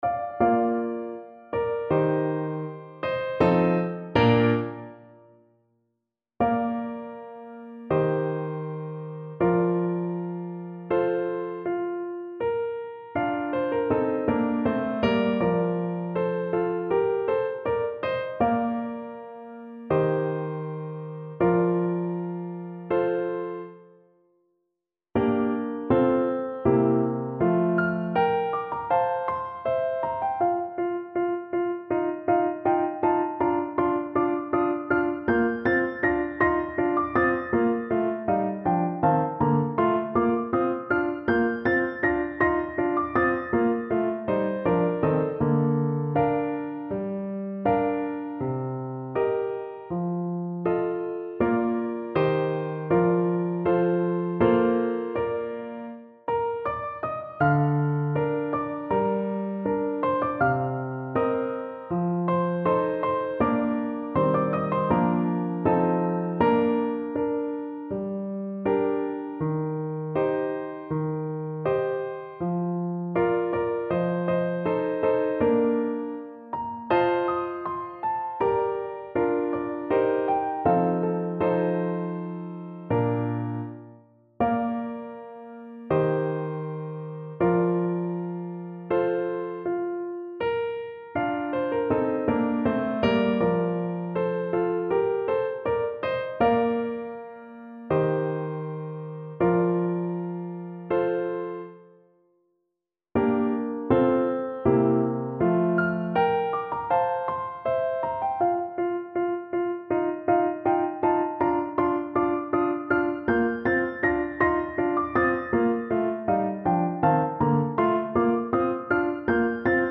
2/4 (View more 2/4 Music)
Larghetto ( = c.40)
Classical (View more Classical French Horn Music)